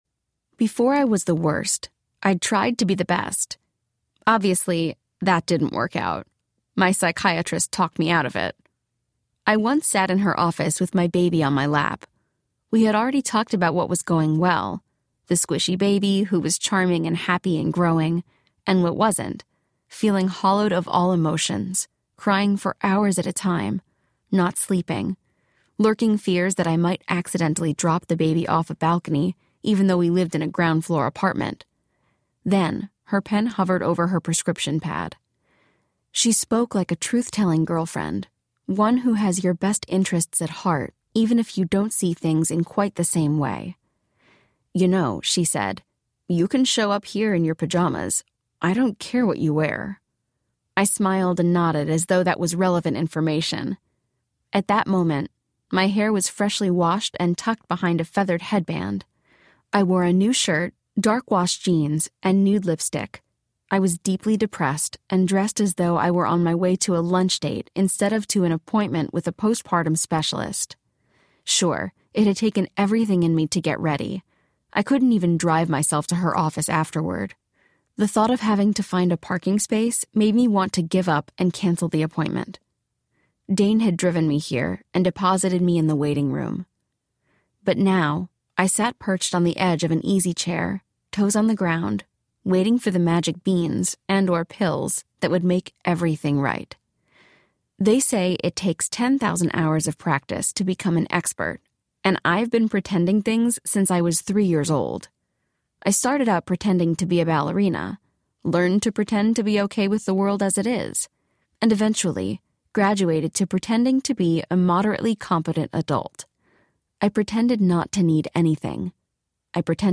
Permission Granted Audiobook
5.3 Hrs. – Unabridged